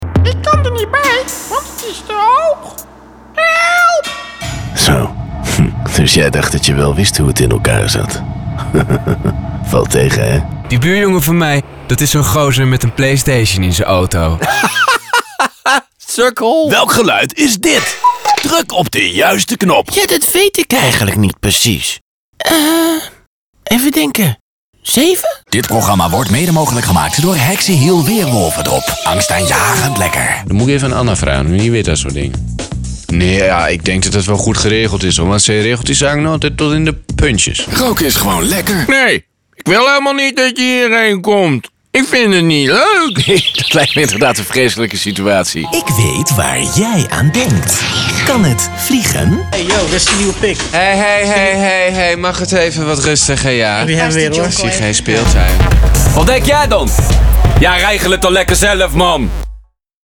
Commerciale, Profonde, Amicale, Chaude, Corporative